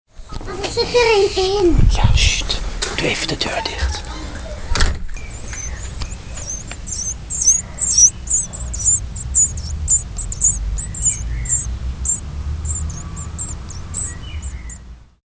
Dieren uit de natuur - Babykoolmezen!
babykoolmezen.mp3